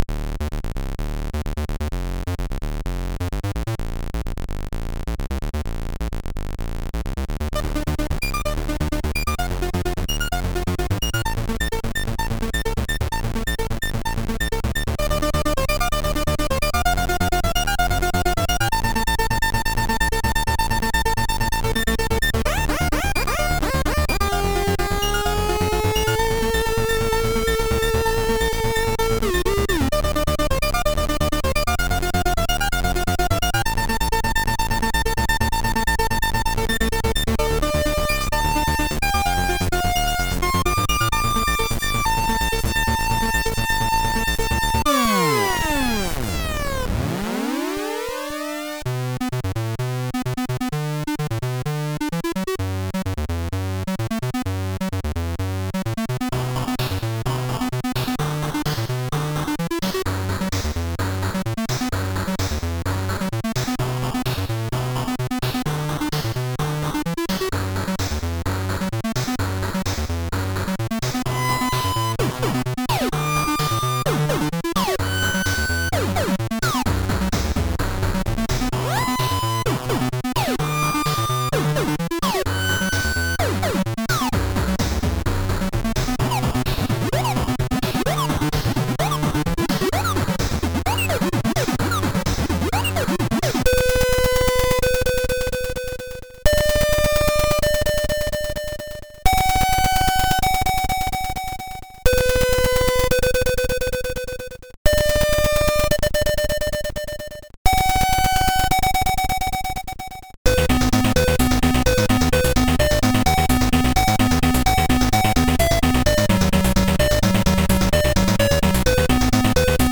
Commodore SID Music File
nwv_jazz 1.mp3